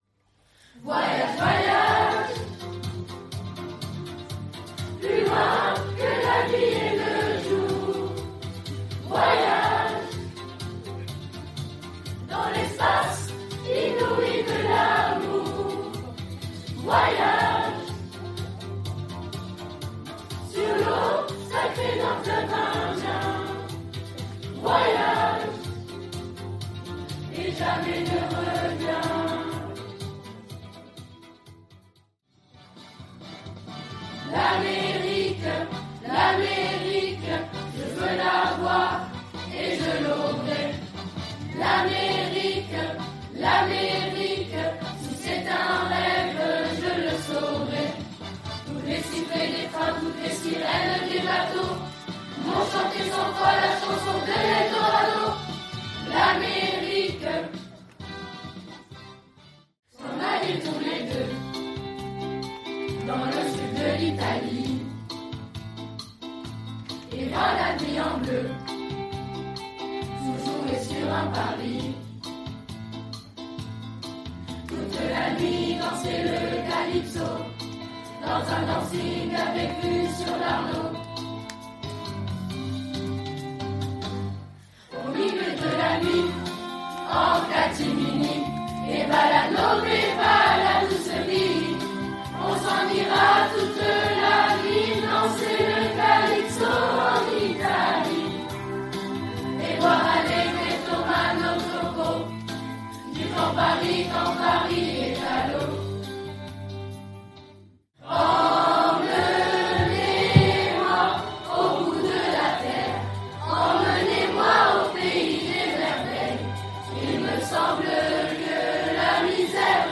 Concert Chorale - Mardi 27 Mai 2025
Les parents, les camarades et les personnels du collège étaient présents et heureux de participer à ce voyage musical.